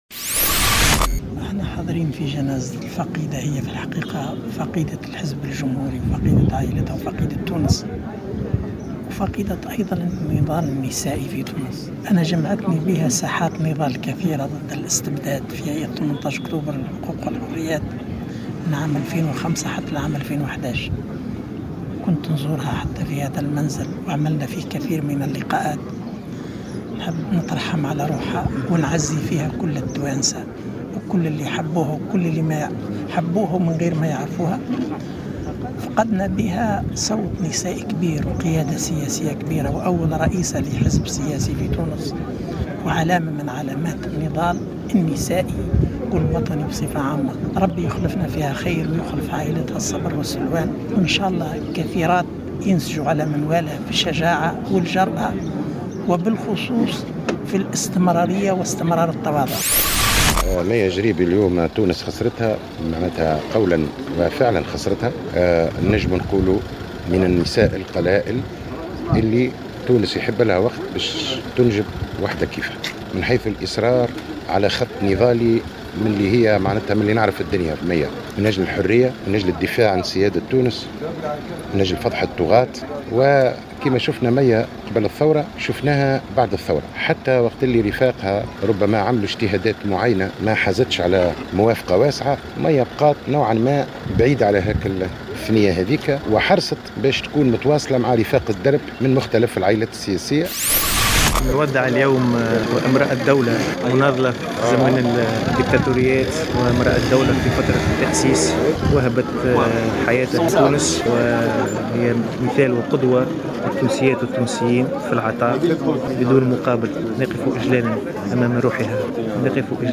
تم اليوم الأحد 20 ماي 2018 تشييع جثمان الفقيدة ميّة الجريبي في جنازة مهيبة بحضور عدد من الشخصيات الحزبية و النقابية.